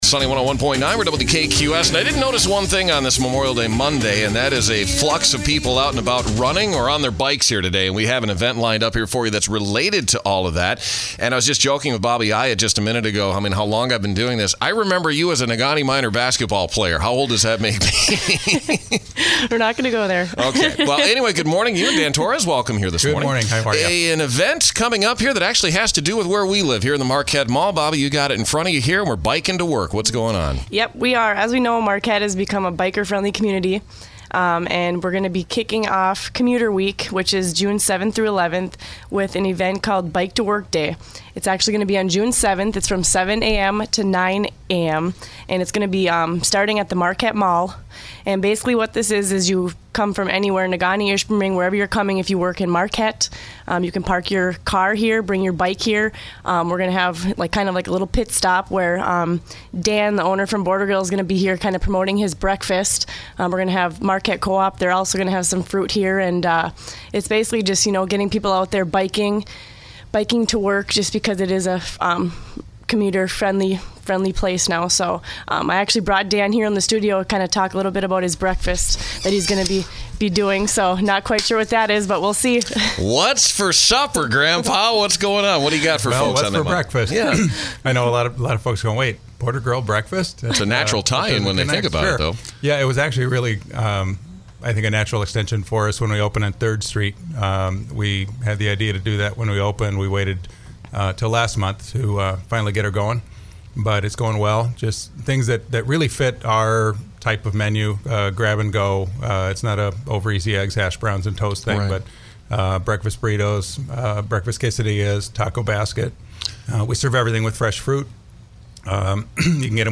NewsOld Interviews Archive